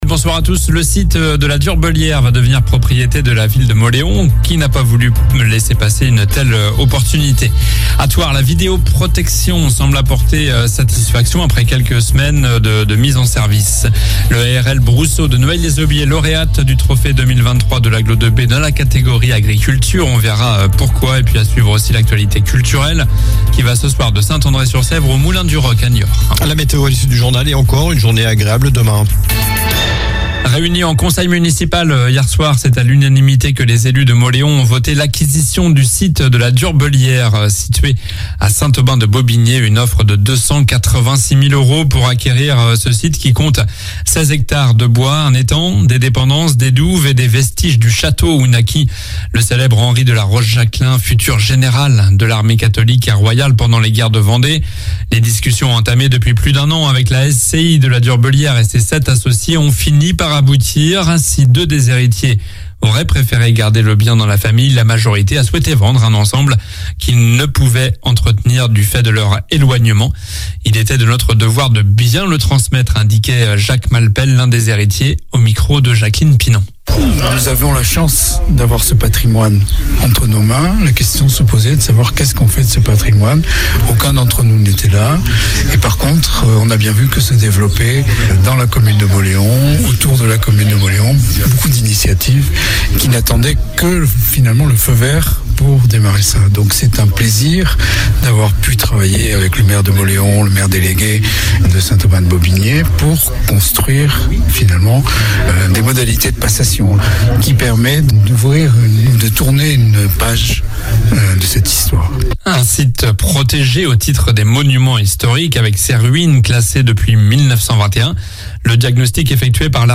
Journal du mardi 16 mai (midi)